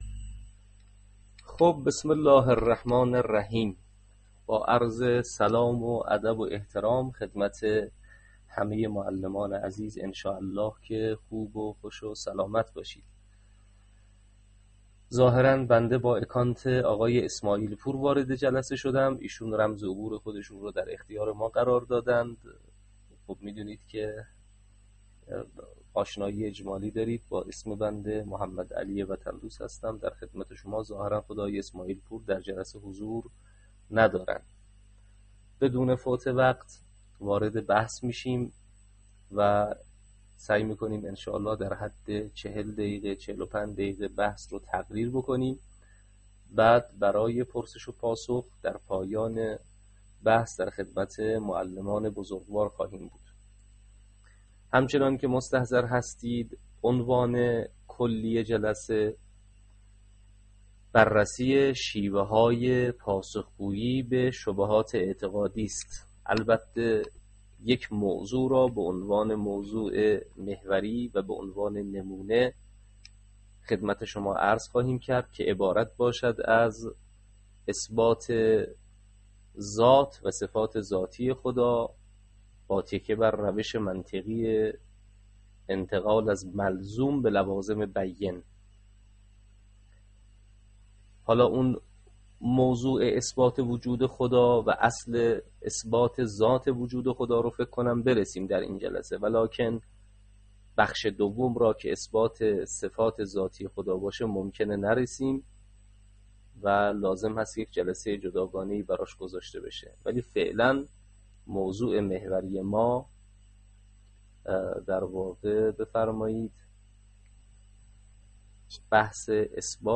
شیوه های پاسخ به شبهات در جمع معلمان آموزش و پرورش به همراه نمونه در اثبات وجود خدا.mp3